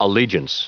Prononciation du mot allegiance en anglais (fichier audio)
Prononciation du mot : allegiance